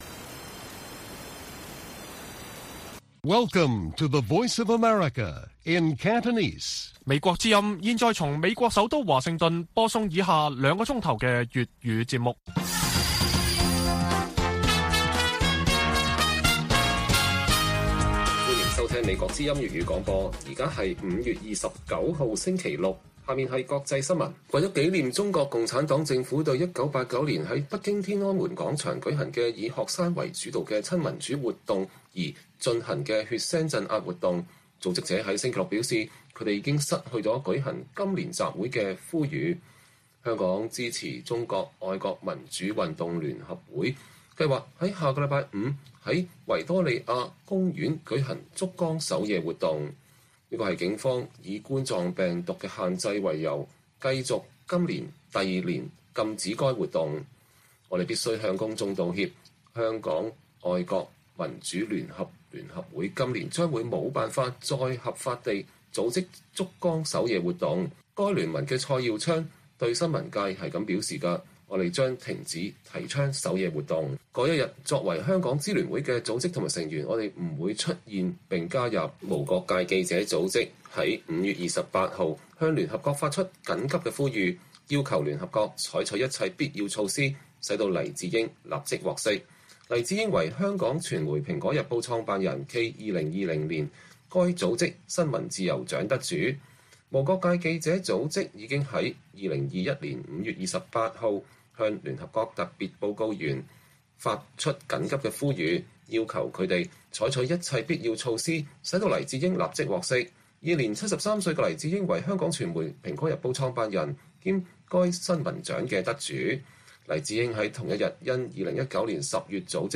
粵語新聞 晚上9-10點 香港支聯會六四遊行集會被禁上訴被駁回 停止宣傳成員六四不入維園